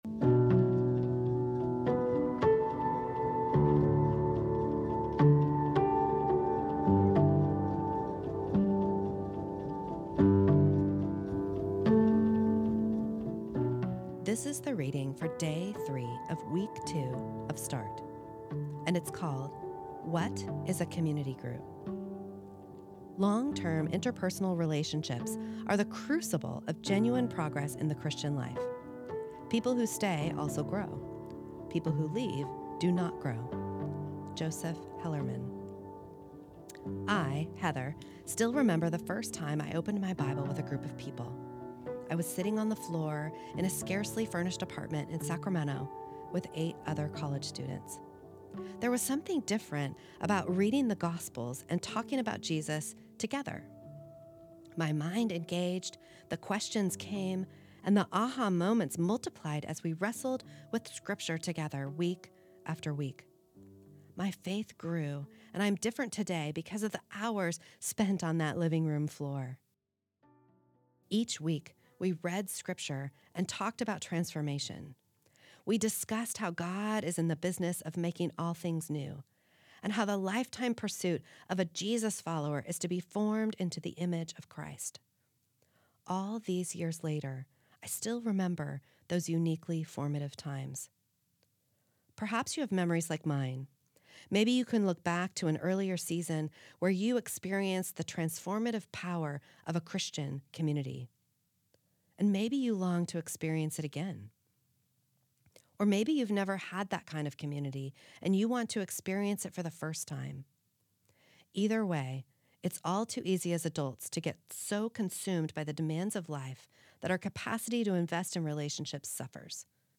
This is the audio recording of the third reading of week two of Start, entitled What is a Community Group?